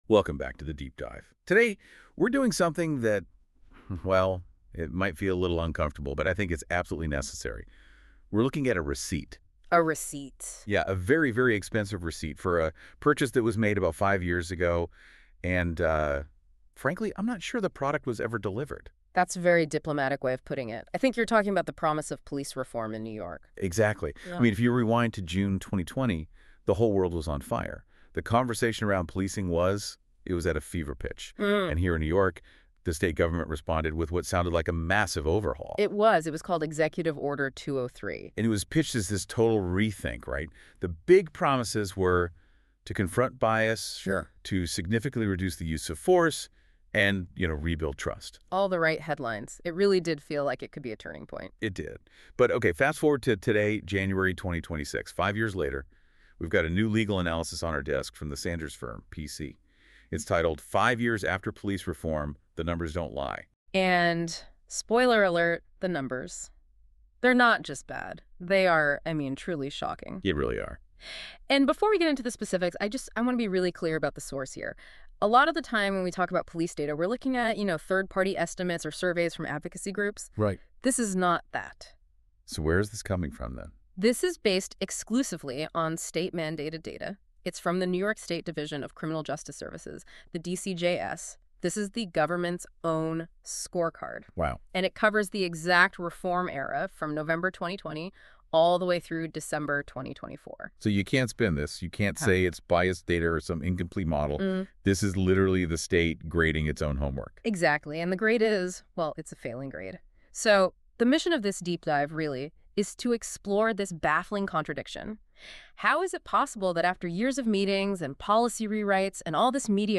Second, a Deep-Dive Podcast that expands on the analysis in conversational form. The podcast explores the historical context, legal doctrine, and real-world consequences in greater depth, including areas that benefit from narrative explanation rather than footnotes.